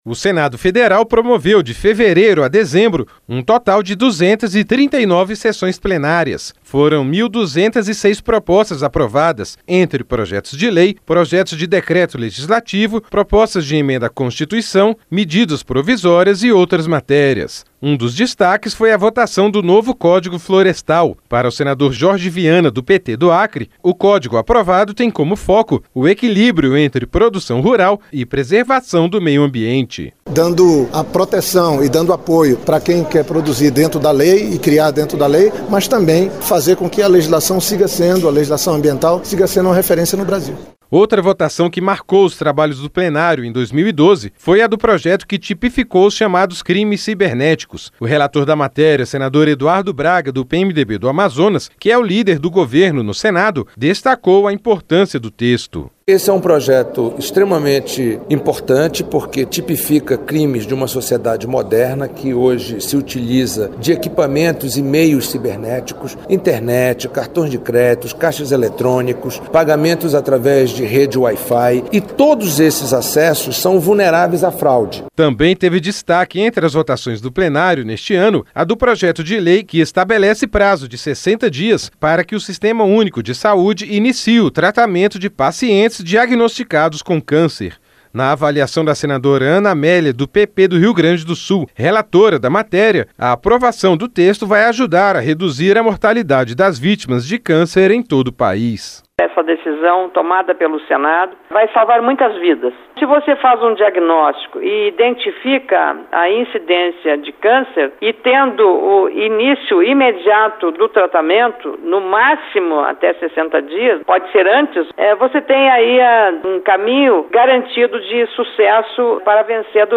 (Repórter) O Senado Federal promoveu, de fevereiro a dezembro, um total de 239 sessões plenárias.